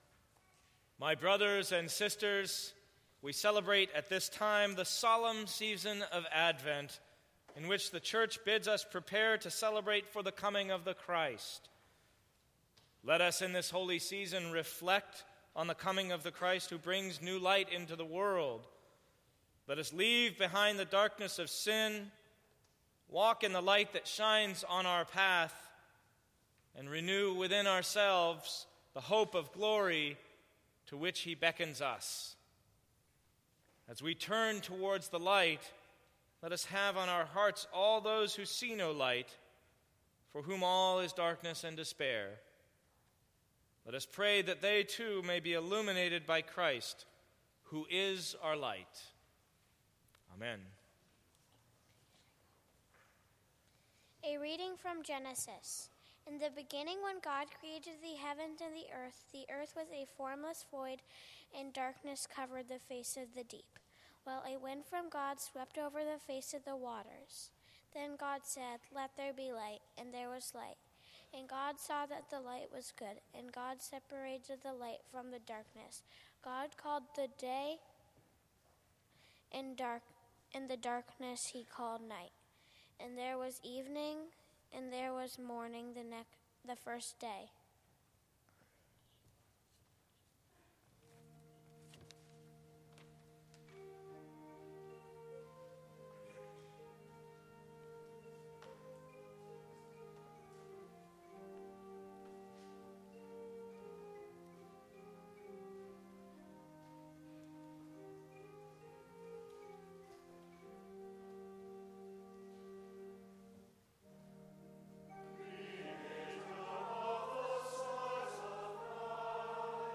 This year for our Lessons and Carols service, we focused on the emerging light.